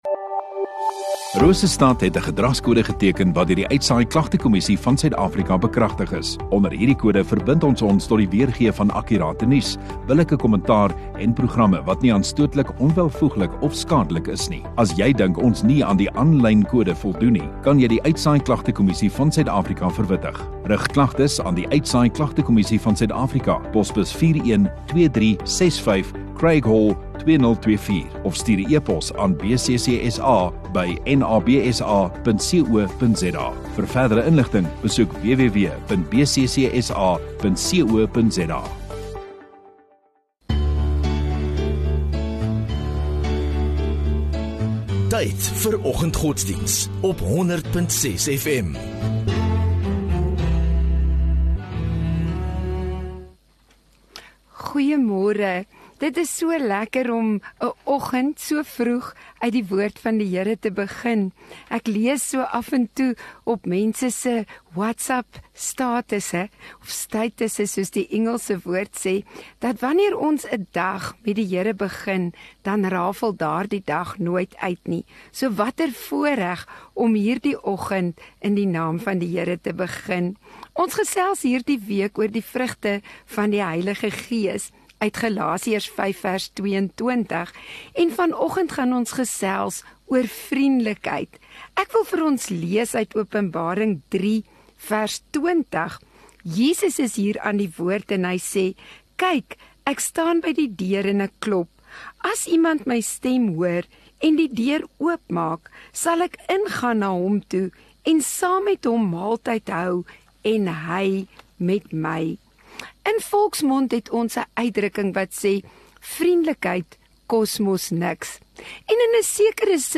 14 Mar Vrydag Oggenddiens